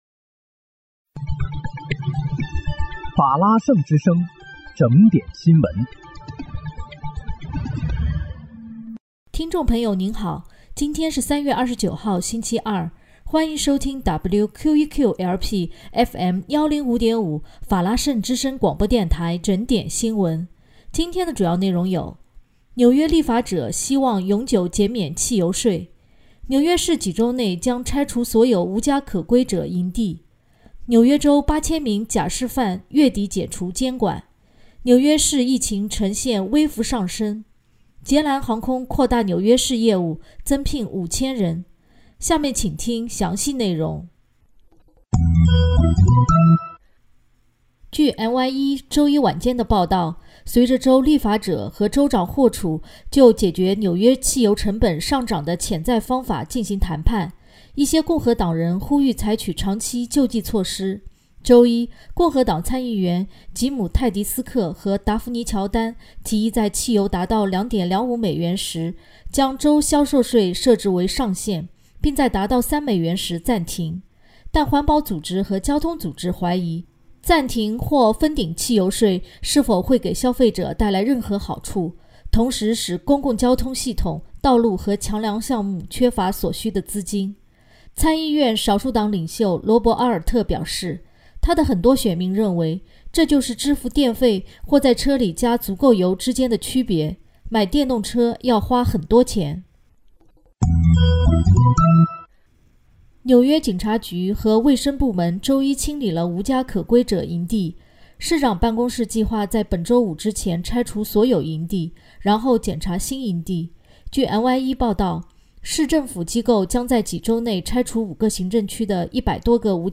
3月29日（星期二）纽约整点新闻